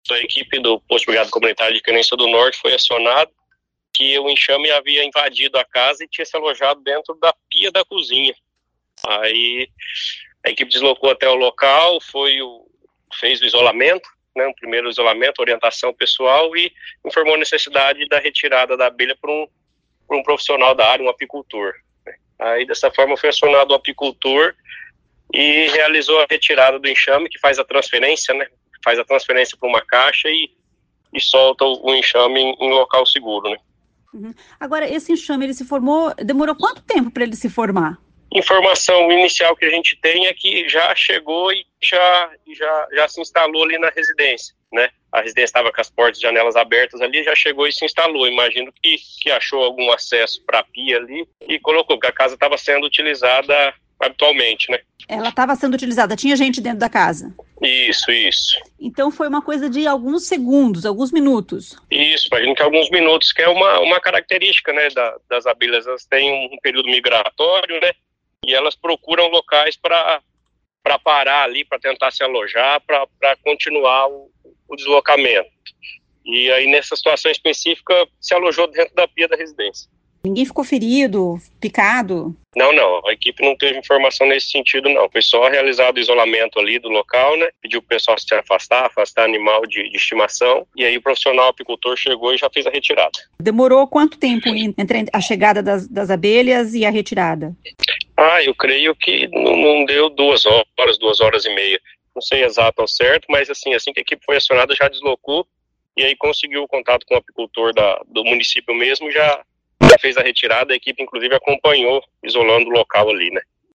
Ouça o que diz o sargento do Corpo de Bombeiros